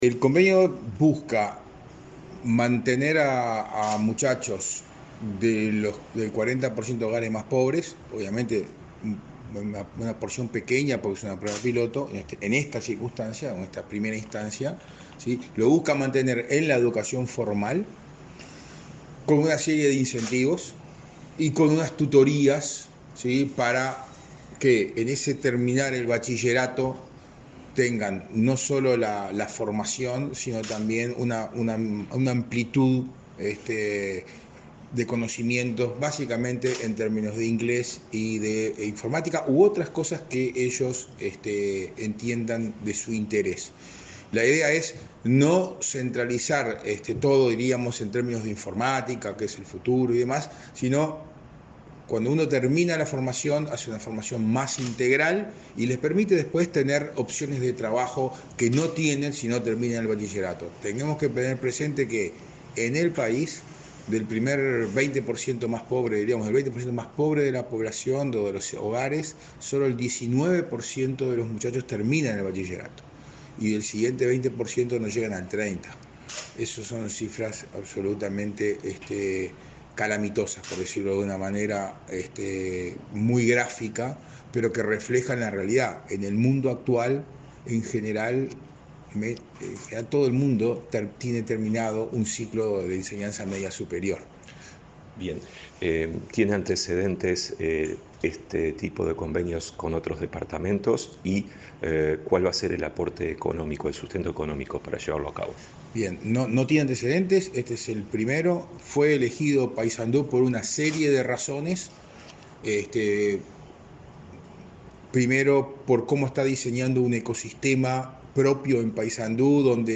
Palabras de autoridades en convenio entre OPP e Intendencia de Paysandú